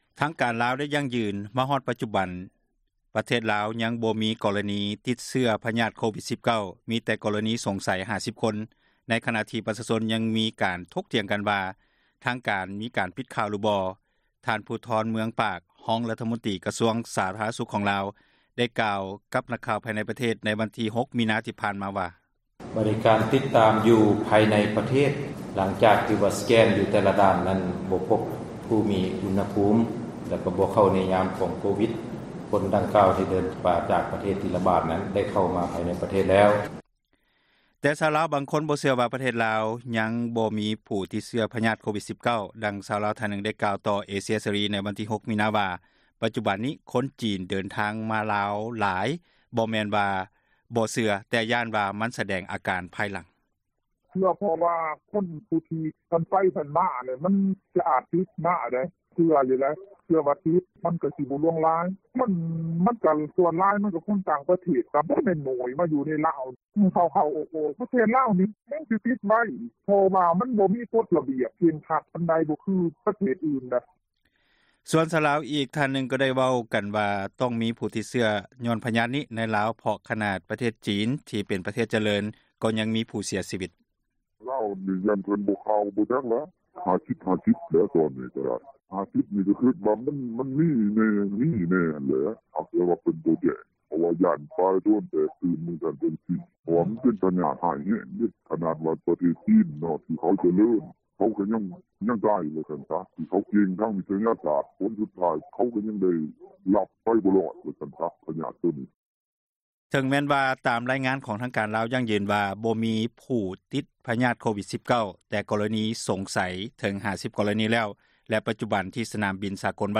ທ່ານ ພູທອນ ເມືອງປາກ, ຮອງຣັຖມົນຕຣີ ກະຊວງສາທາຣະນະສຸຂ ຂອງລາວ ໄດ້ກ່າວຕໍ່ນັກຂ່າວ ພາຍໃນປະເທດ ໃນວັນທີ່ 6 ມີນາ ນີ້ວ່າ:
ແຕ່ຊາວລາວບາງຄົນ ບໍ່ເຊື່ອວ່າ ປະເທດລາວ ຍັງບໍ່ມີຜູ້ຕິດເຊື້ອພຍາດ ໂຄວິດ-19, ດັ່ງ ຊາວລາວ ທ່ານນຶ່ງ ກ່າວຕໍ່ວິທຍຸເອເຊັຽເສຣີ ໃນ ວັນທີ 6 ມີນາ ວ່າປັດຈຸບັນນີ້ ຄົນຈີນຍັງເດີນທາງມາລາວຢູ່ ບໍ່ແມ່ນວ່າບໍ່ເຊື່ອແຕ່ຢ້ານວ່າ ມັນສະແດງ ອາການພາຍຫລັງ: